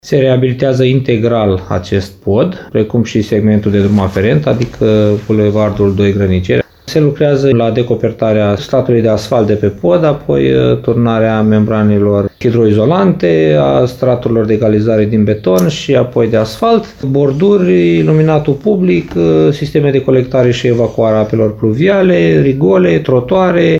Primarul CĂTĂLIN COMAN a declarat că proiectul este finanțat din fonduri guvernamentale în valoare de 3 milioane și jumătate lei și a detaliat lucrările executate.